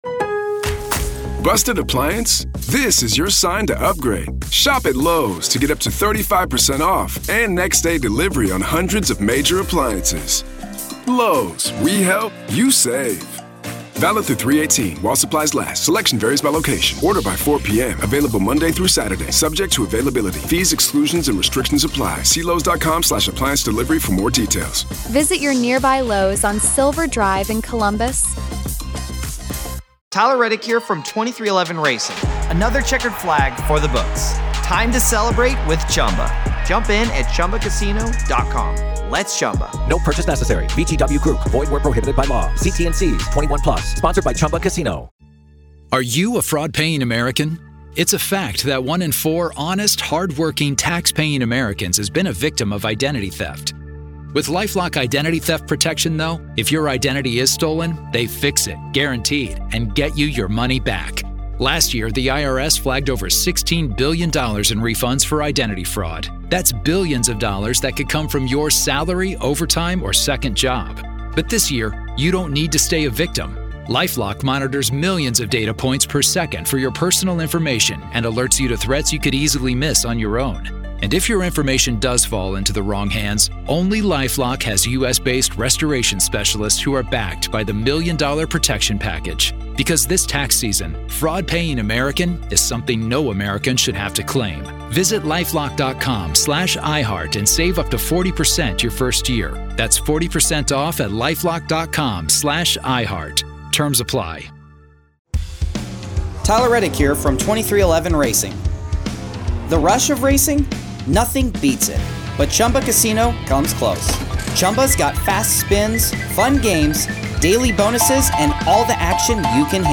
In the Red, White, Salute the Blue Flash-Back Weekend, we pay tribute to those who have dedicated their lives to law enforcement. This event celebrates our favorite interviews from the past, spotlighting the stories, experiences, and insights shared by our esteemed guests from...